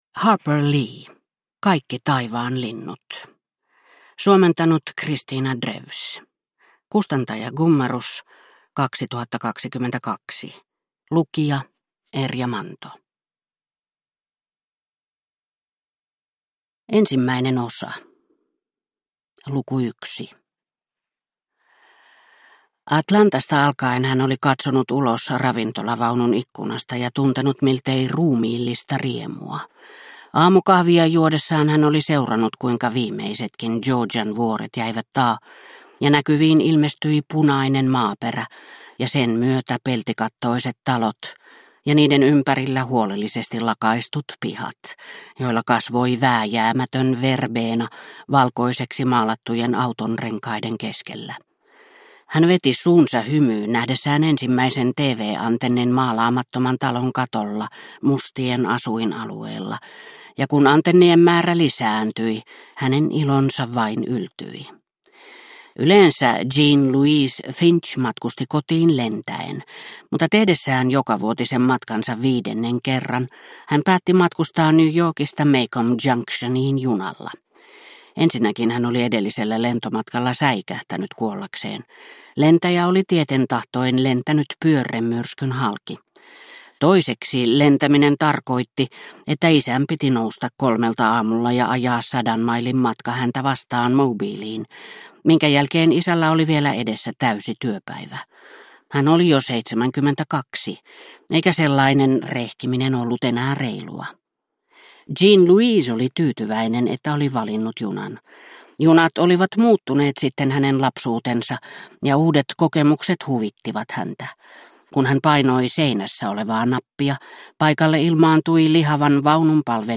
Kaikki taivaan linnut – Ljudbok – Laddas ner